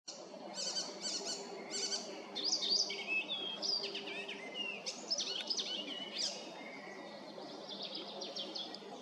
Птицы -> Славковые ->
пересмешка, Hippolais icterina